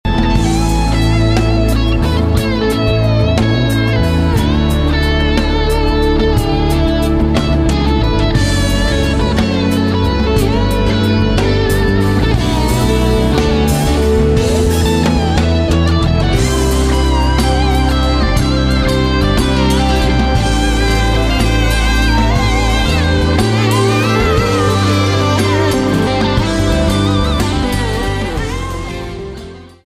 STYLE: Pop
huge yet somehow understated 11 minute opener